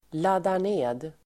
Uttal: [ladar_n'e:d el. häm:tar]